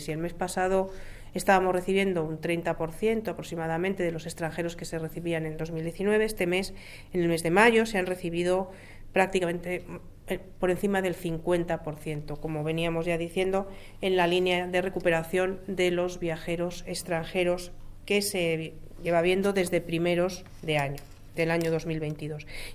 AUDIOS. Mar Álvarez, concejala de Turismo